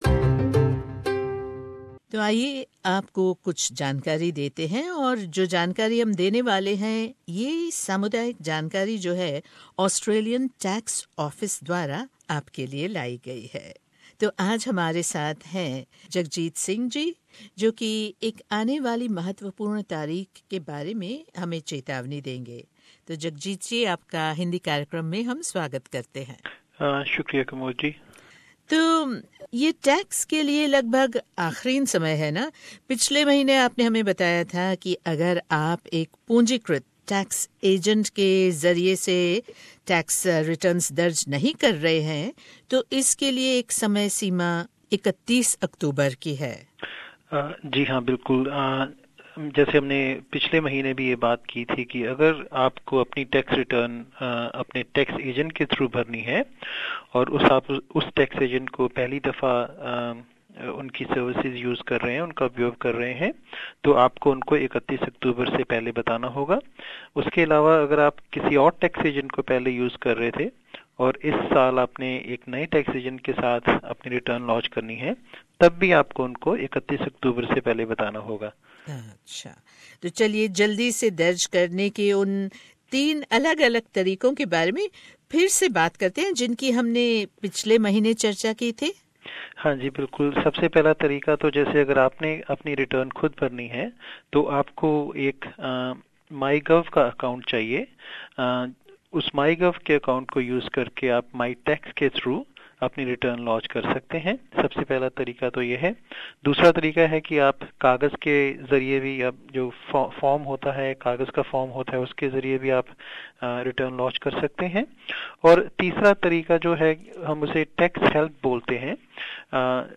The following community Information is brought to you by the Australian Taxation Office. Interview